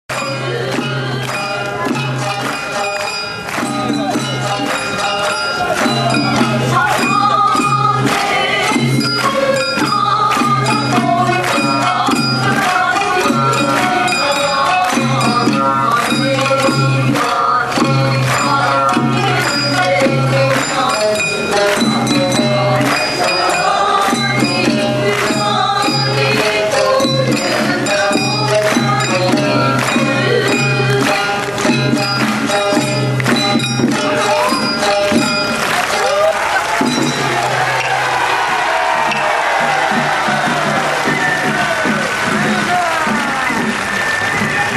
必ずどこかの本踊りで奉納される「ぶらぶら節」に合わせ、桟敷席、長坂連が一帯となって歌う大合唱も醍醐味のひとつ。